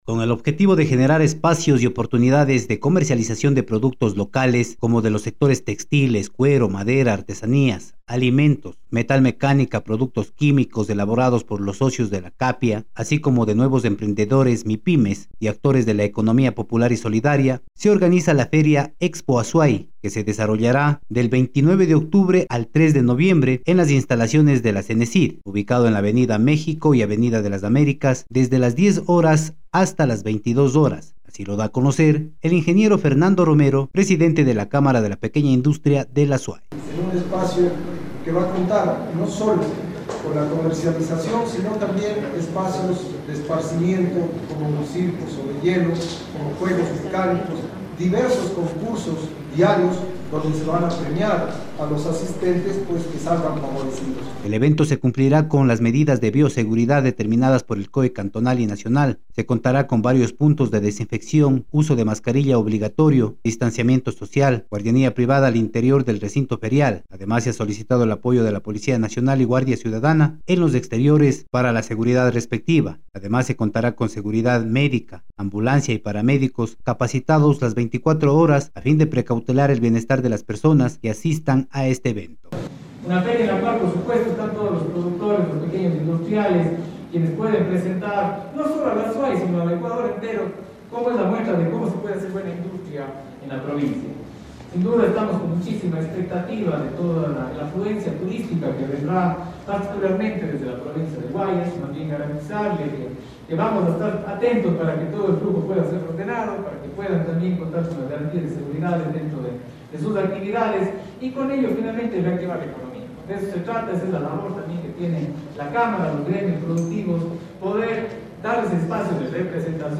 Mediante una rueda de prensa desarrollada en la Gobernación del Azuay se invitó a la colectividad del Austro y del País, a participar de la Expo Azuay 2021 por la Independencia de Cuenca, evento que contara con todos los protocolos de bioseguridad para los visitantes y los usuarios de los 150 stands.